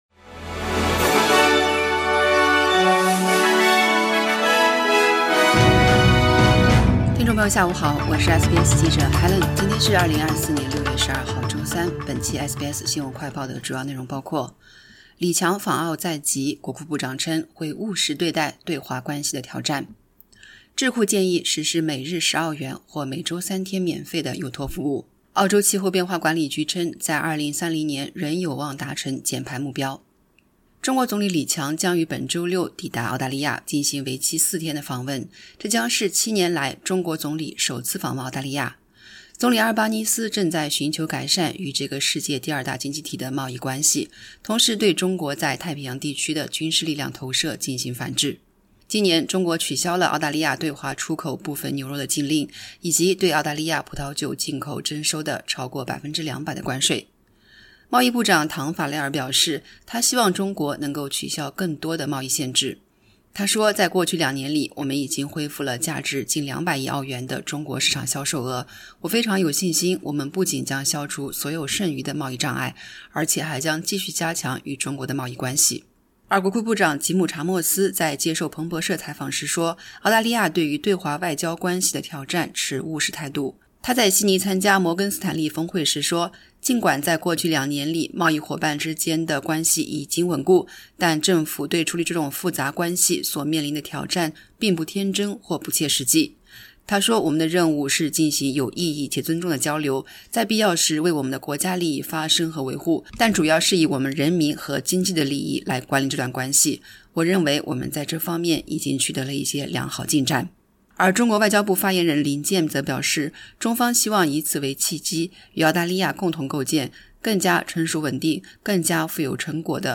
【SBS新闻快报】李强访澳在即 国库部长称会务实对待对华关系的挑战